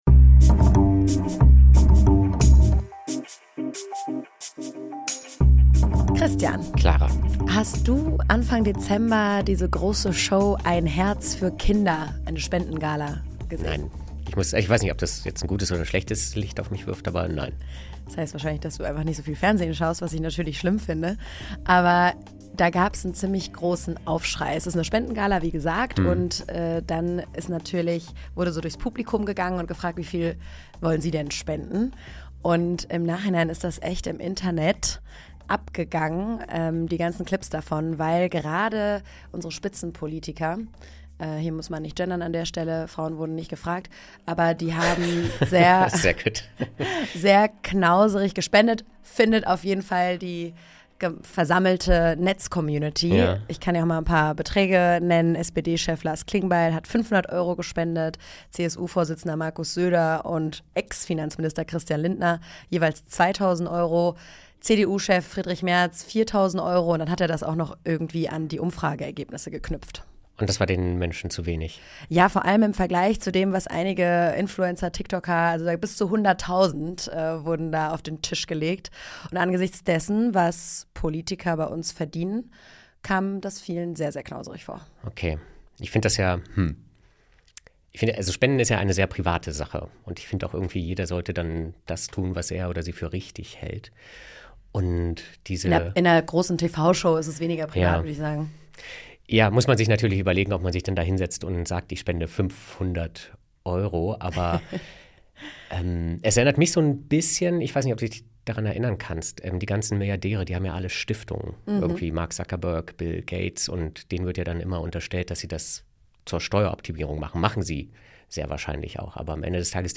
Dann bewertet das "Klima-Labor" bei Apple Podcasts oder SpotifyDas Interview als Text?